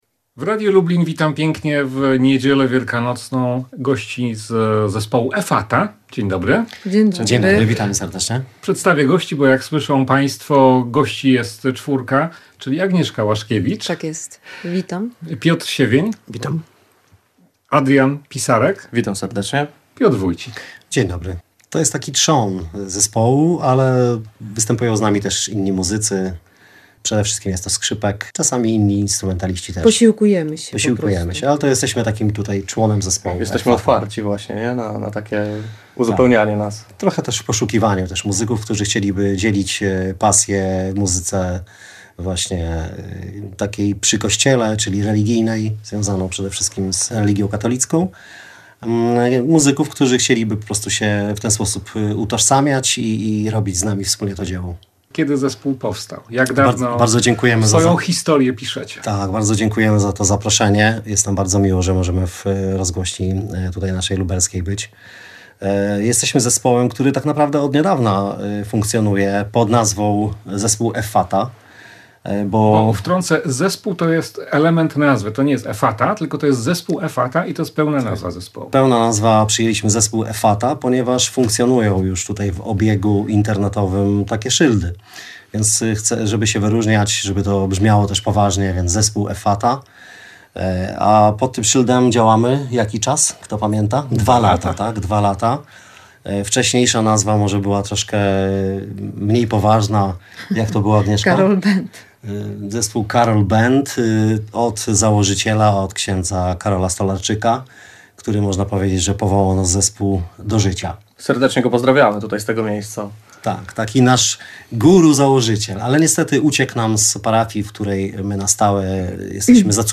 Z zamojskim Zespołem Effatha rozmawia